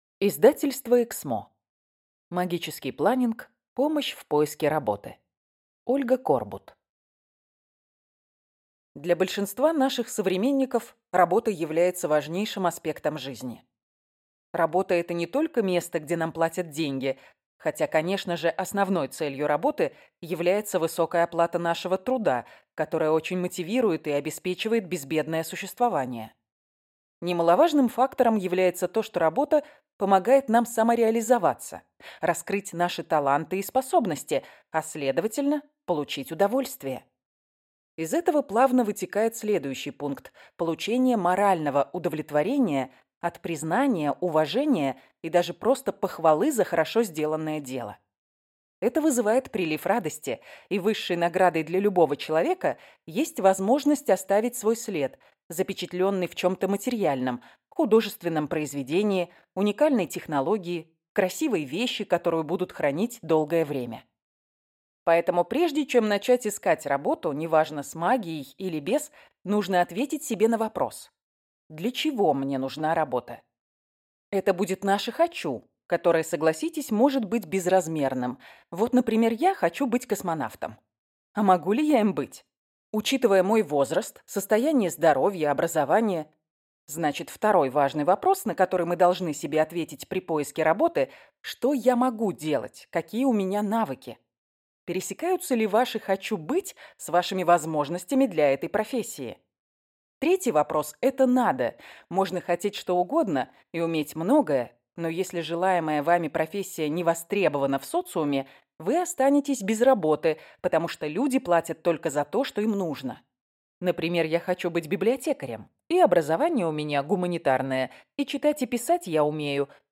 Аудиокнига Магический планинг. Помощь в поиске работы | Библиотека аудиокниг